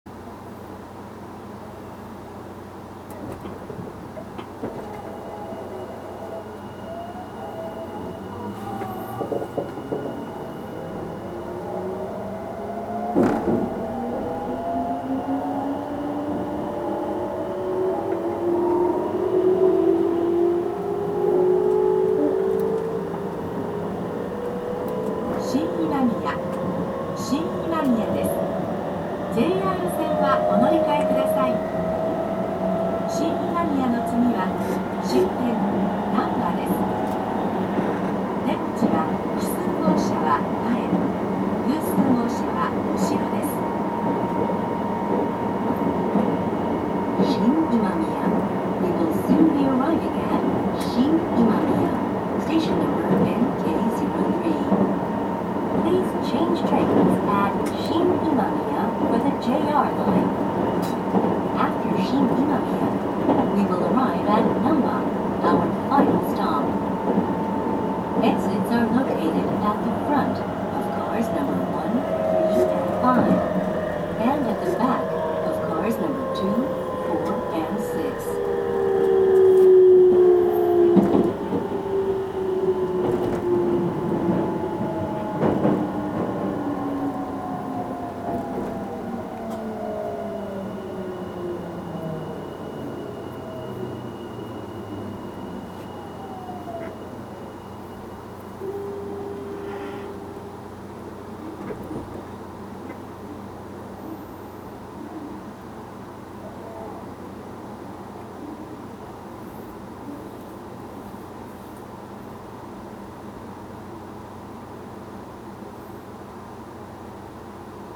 走行機器はGTO素子によるVVVFインバータ制御で、定格180kWのかご形三相誘導電動機を制御します。
走行音
録音区間：天下茶屋～新今宮(ラピートβ72号)(お持ち帰り)